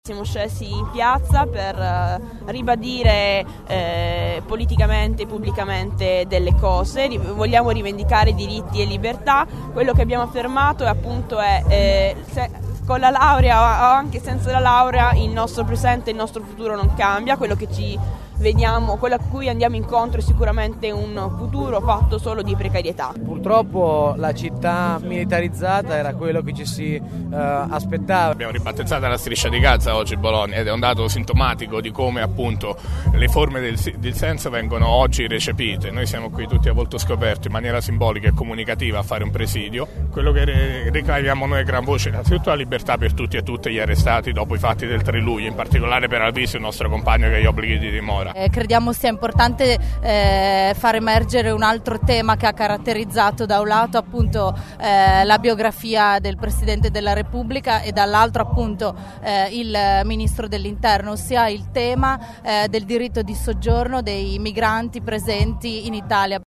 Le voci del secondo presidio
Voci_manifestanti_TPO.mp3